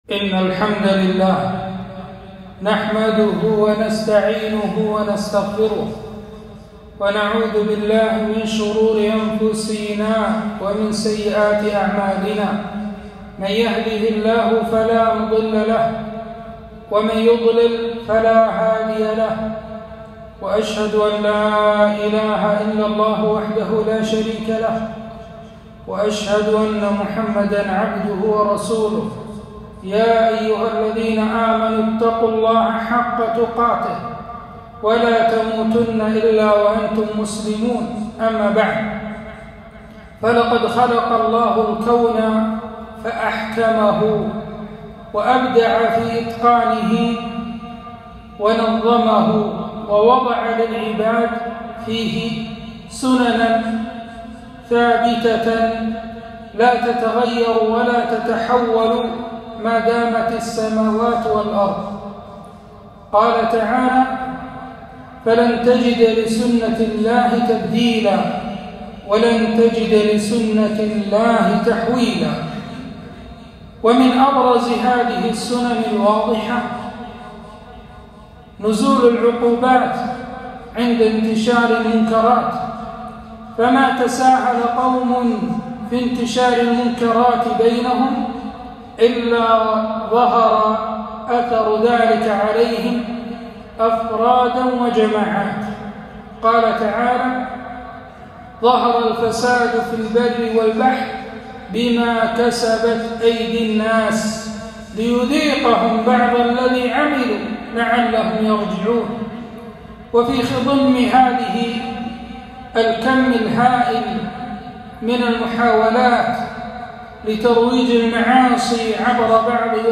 خطبة - آثار الذنوب والمعاصي